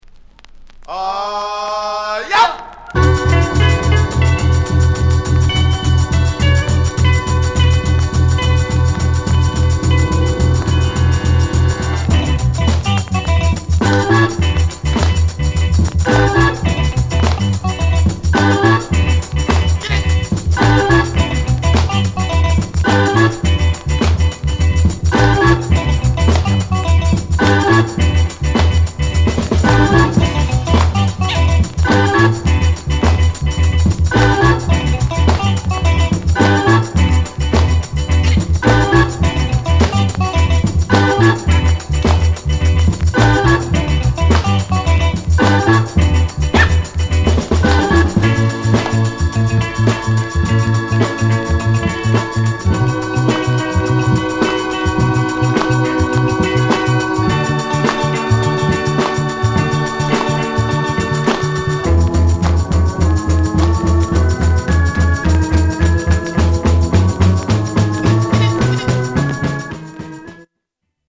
KILLER FUNKY INST